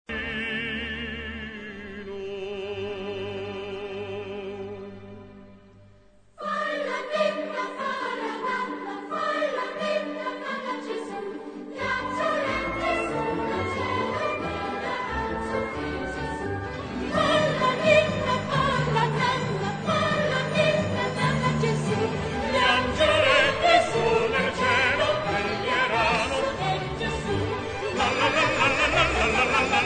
music: Traditional
key: G-major